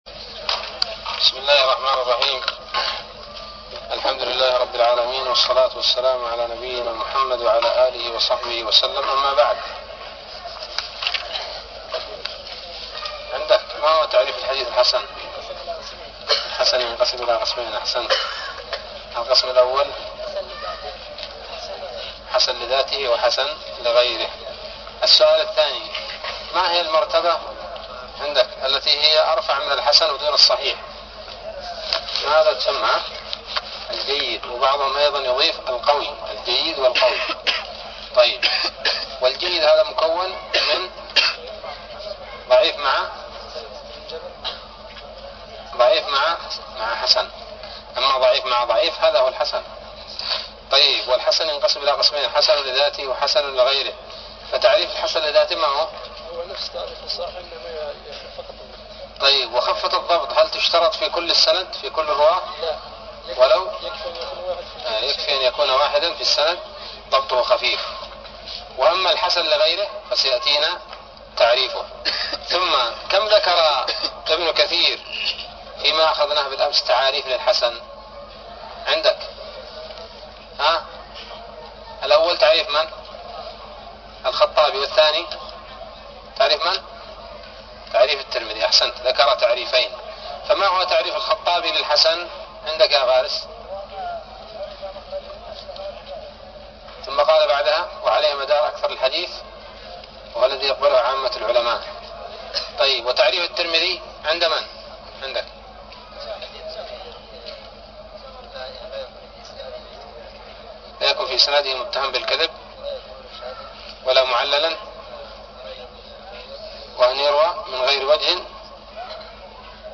الدرس العاشر من الباعث الحثيث